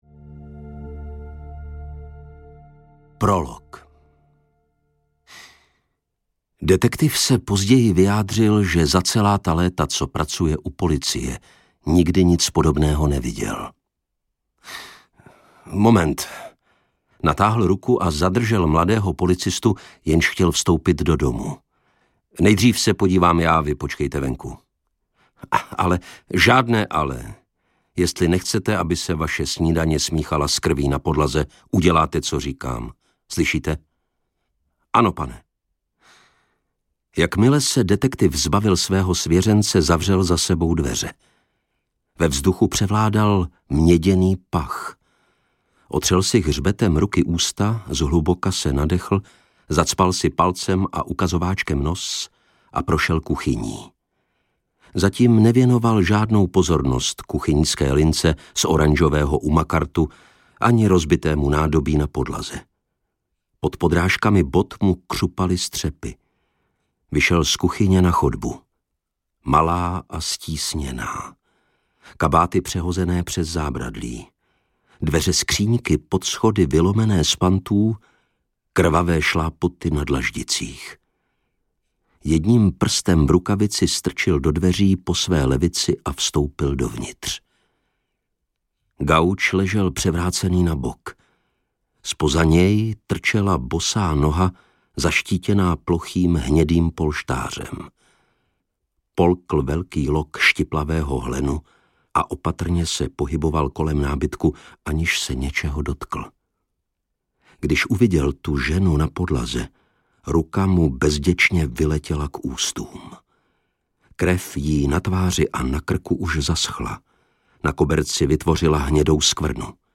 Pohřbení andělé audiokniha
Ukázka z knihy
pohrbeni-andele-audiokniha